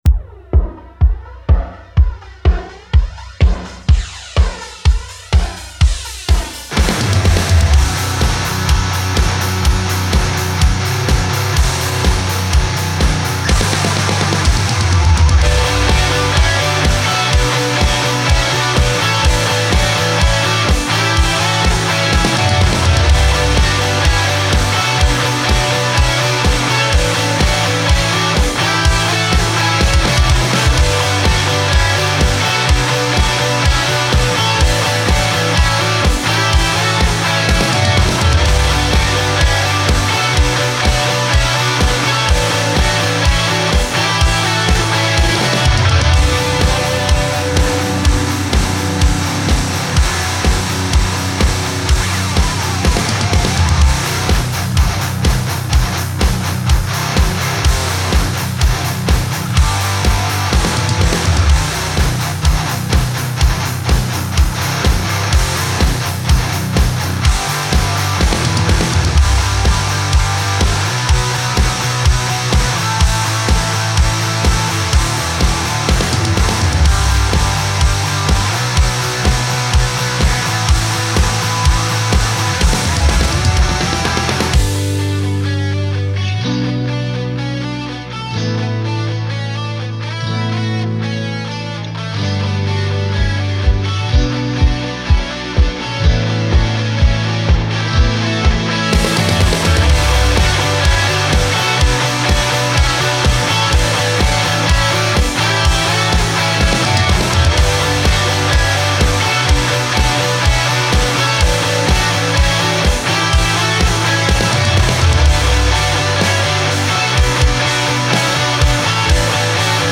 METAL - Mixing Feedback dringend gesucht!